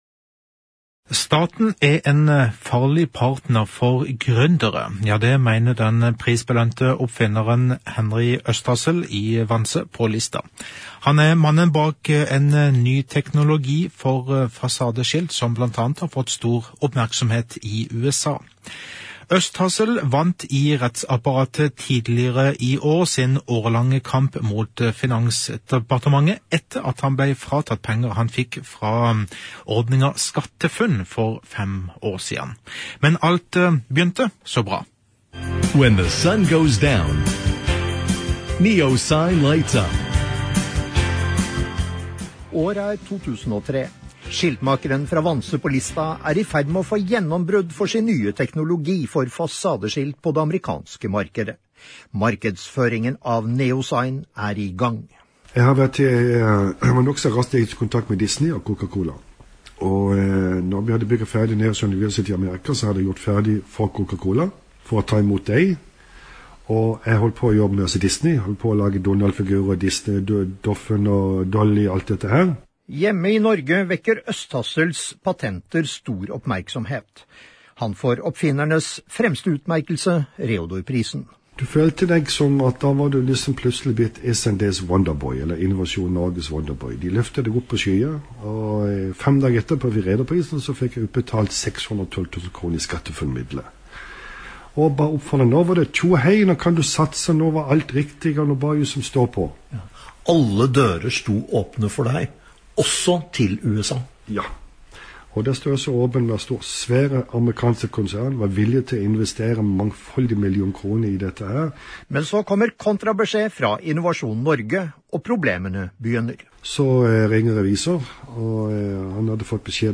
Morgensending med to innslag